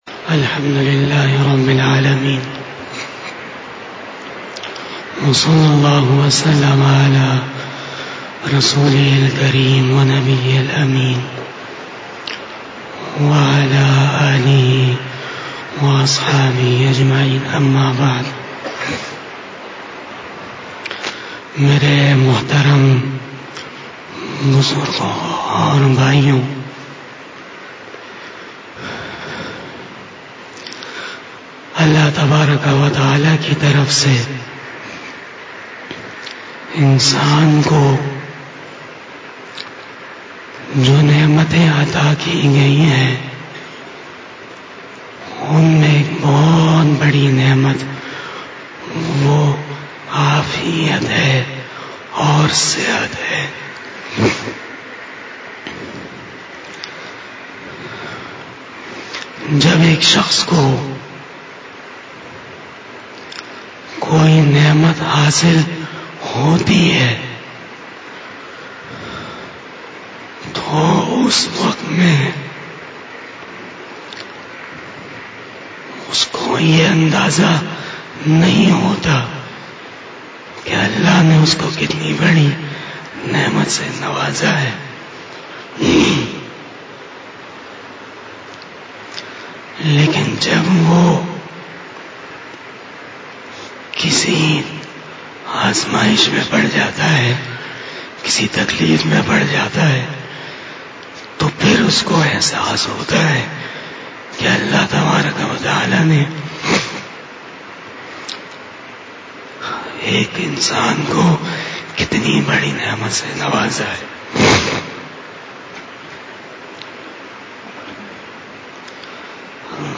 08 BAYAN E JUMA TUL MUBARAK 19 February 2021 06 Rajab 1442H)
02:08 PM 888 Khitab-e-Jummah 2021 --